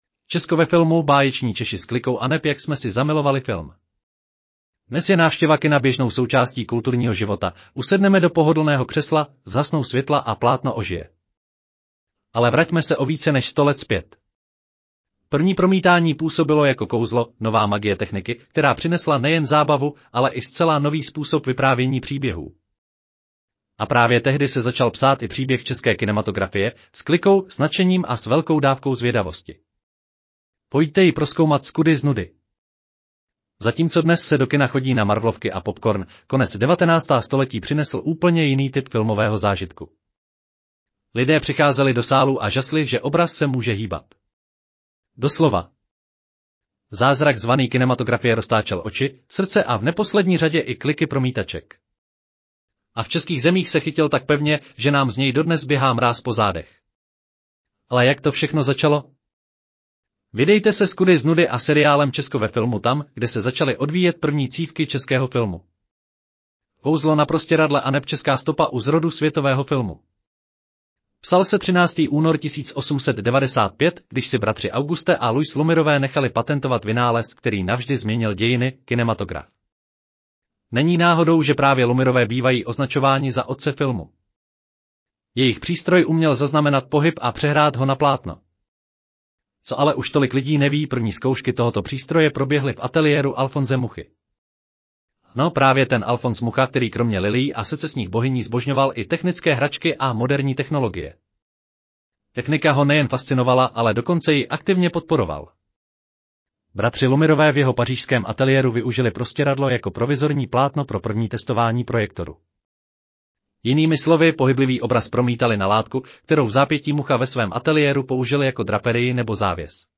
Audio verze článku Česko ve filmu: báječní Češi s klikou aneb jak jsme si zamilovali film